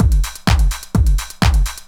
Miltons Beat 3_127.wav